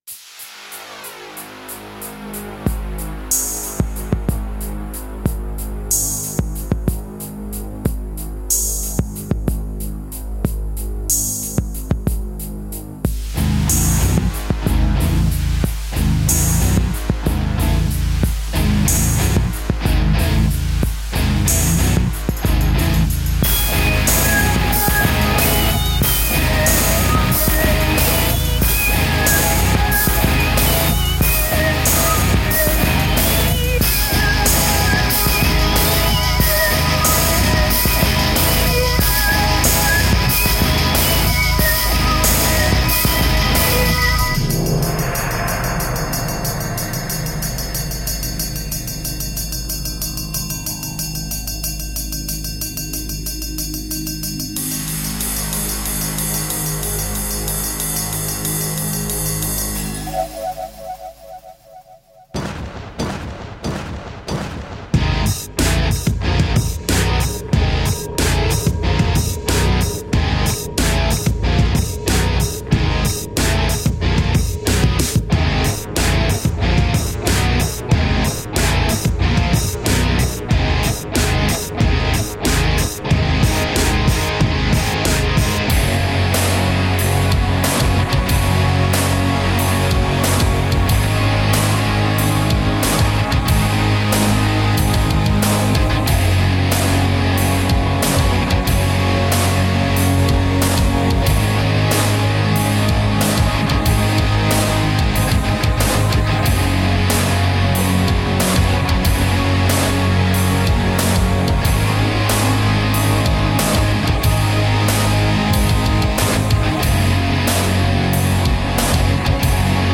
Guitar-edged industrial electrorock.